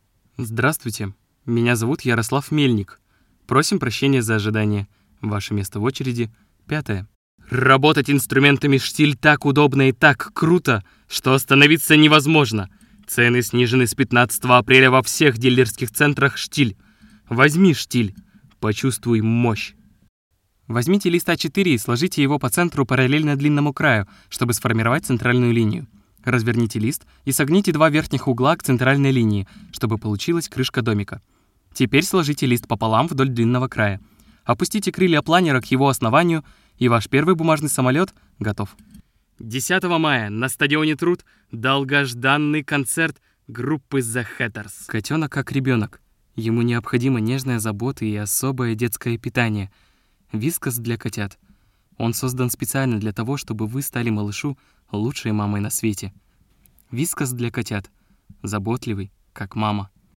Пример звучания голоса
Муж, Другая/Молодой